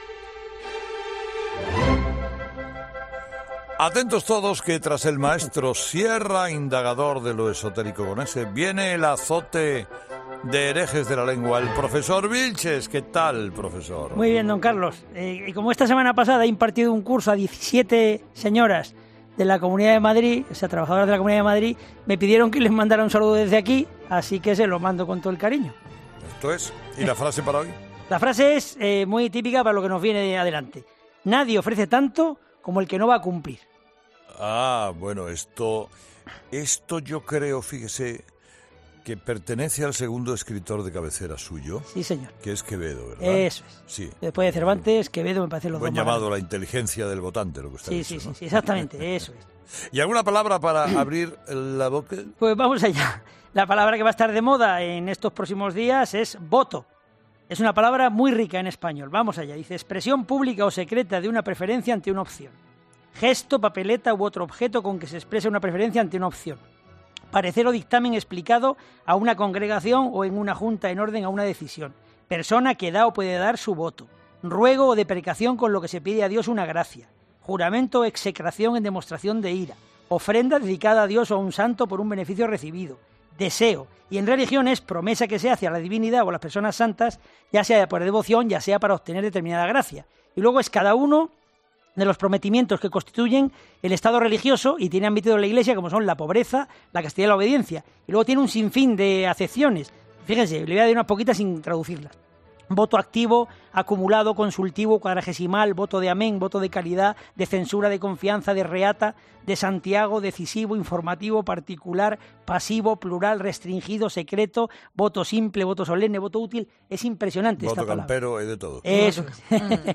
El programa hoy lo dedicamos a las MULETILLAS, y tenemos ejemplos y más ejemplos. Tan es así que hemos encadenado el sonido de algunos de nuestros tertulianos recurriendo al poco estético digamos.